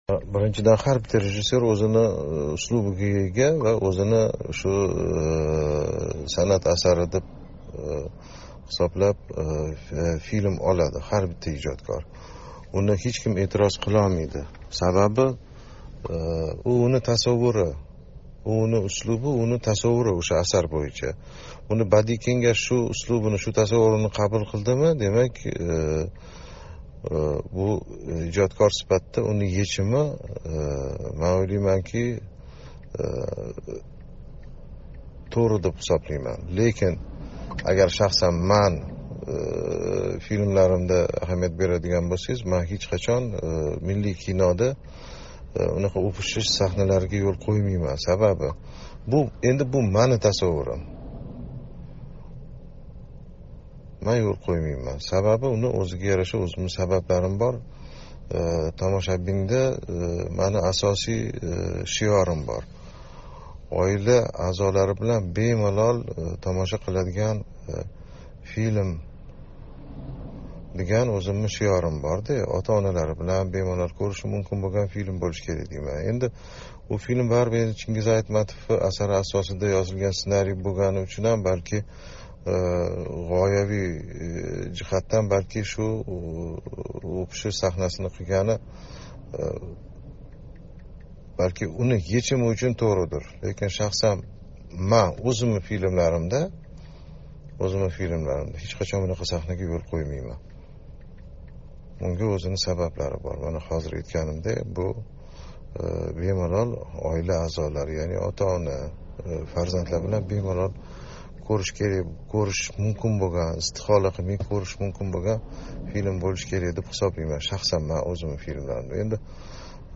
Рустам Сагдиев билан суҳбат